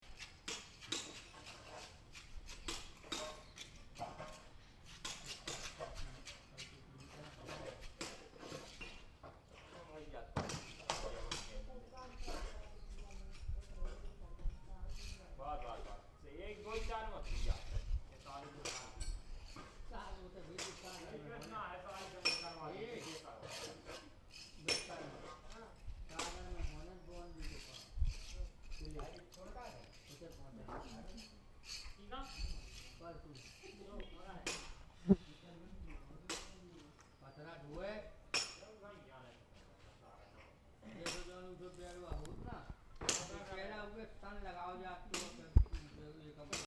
31/01/2014 13:00 Statue d'une femme qui se met du khôl. Derrière moi, des ouvriers rénovent et taillent la pierre. Pendant que je dessine, je me plais à imaginer qu'il y a plus de 1000 ans, l'environnement sonore était le même.